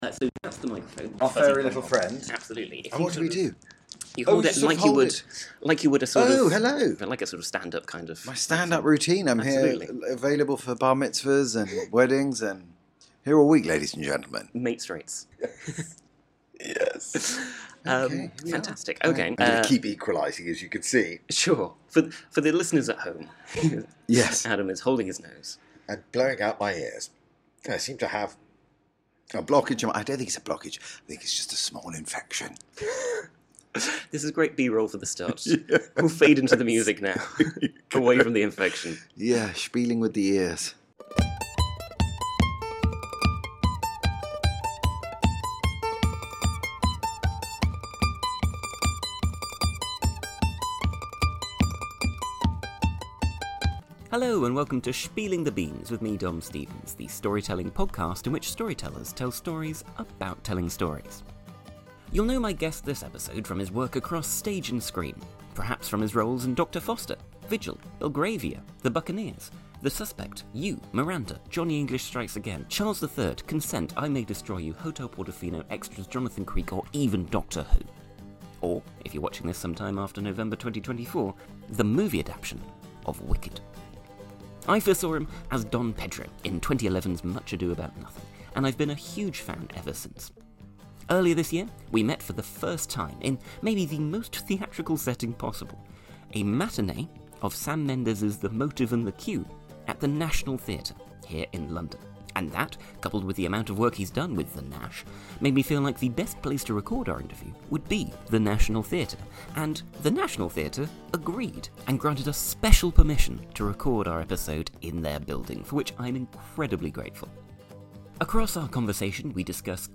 My guest this episode is Adam James, star of Vigil, The Buccaneers, Charles III, Doctor Foster, Extras, Wicked, Johnny English Strikes Again, and countless more productions. Across our conversation, we discuss what makes a good director, approaching Shakespeare, working with Rupert Goold, the theatrical value of table tennis, performing on Broadway versus West End, playing Ariana Grande’s father in one of the biggest productions ever filmed, and so much more. Recorded with special permission in The National Theatre, London, in September 2023.